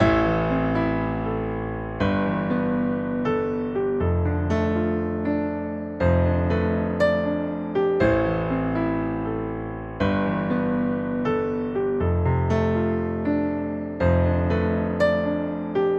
Hard Breathing / Painful
描述：Heavy breathing. Man in pain or with a heart attack.
标签： pains heartattack hardbreathing maninpain
声道立体声